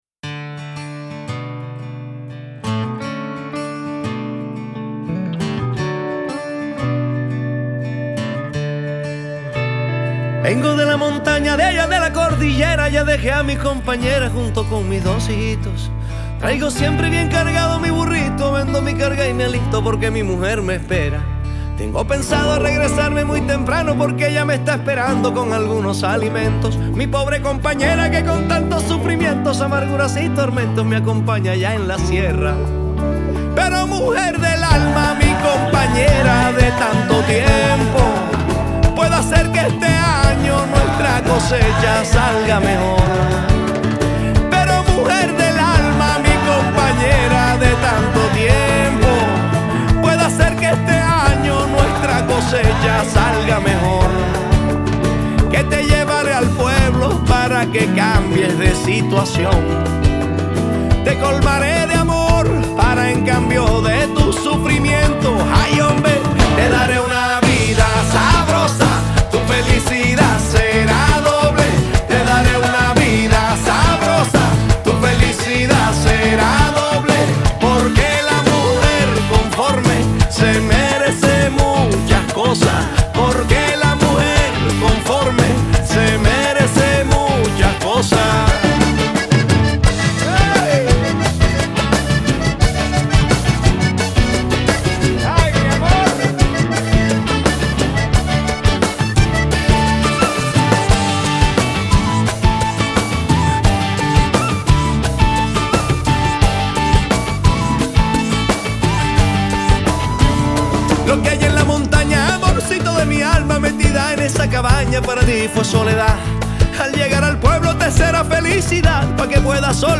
Vallenato